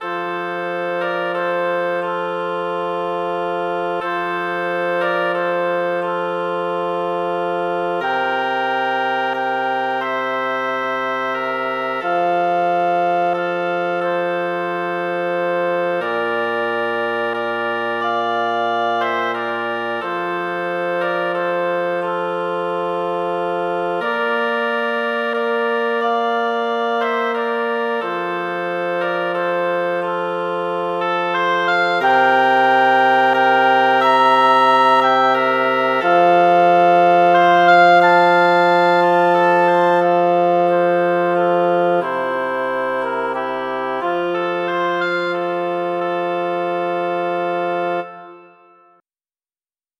arrangements for wind trio
flute:
oboe:
bassoon: